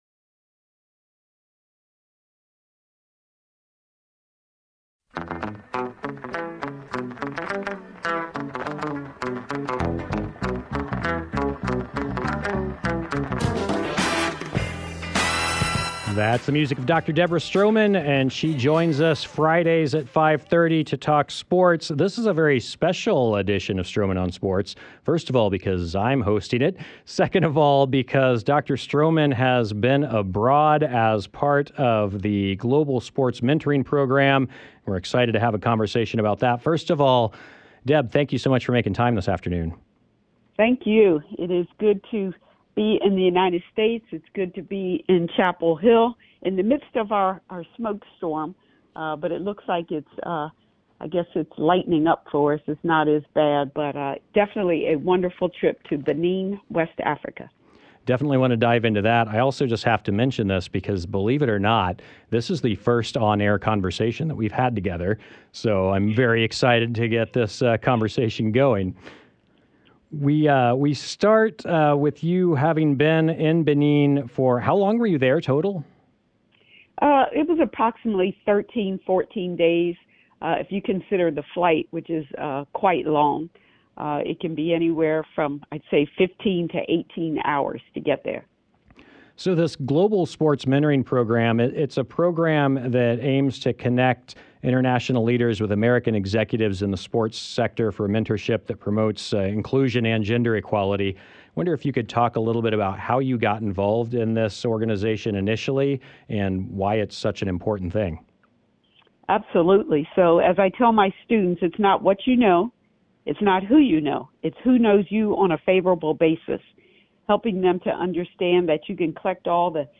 Check out highlights of the conversation below, which have been lightly edited for clarity, and click here to listen to the full conversation!